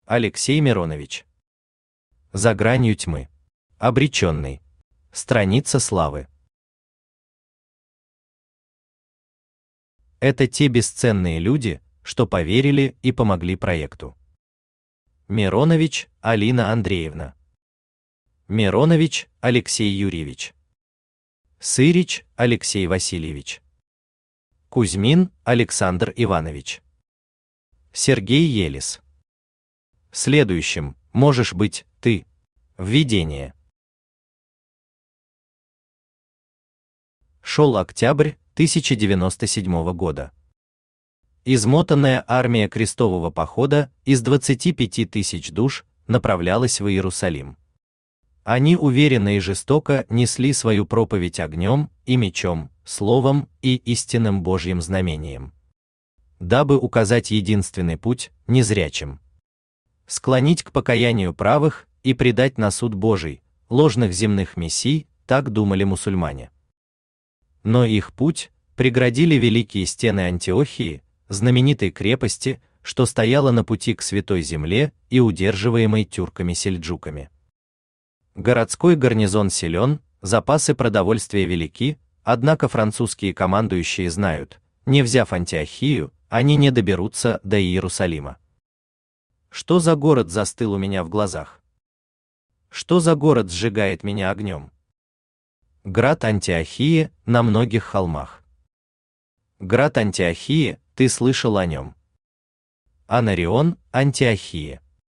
Аудиокнига За гранью тьмы. Обречённый | Библиотека аудиокниг
Обречённый Автор Алексей Васильевич Миронович Читает аудиокнигу Авточтец ЛитРес.